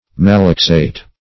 Search Result for " malaxate" : The Collaborative International Dictionary of English v.0.48: Malax \Ma"lax\, Malaxate \Ma*lax"ate\, v. t. [L. malaxare, malaxatum, cf. Gr.